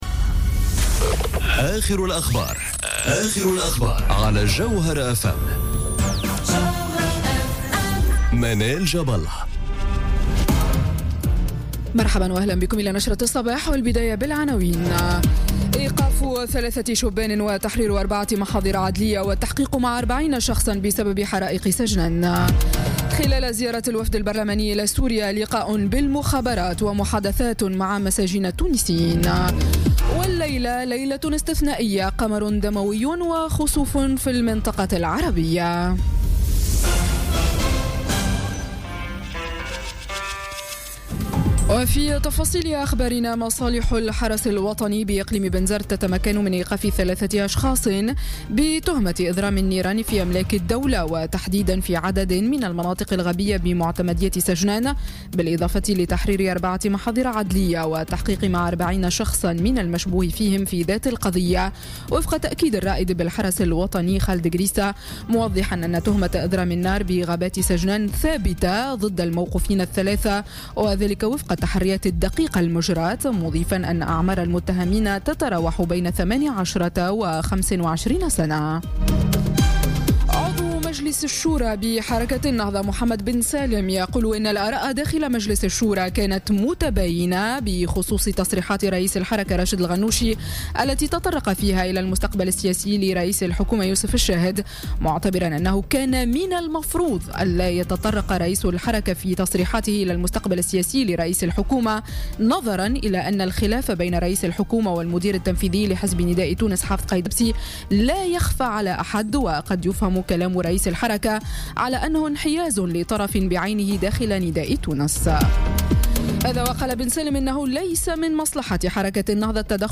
نشرة أخبار السابعة صباحا ليوم الاثنين 7 أوت 2017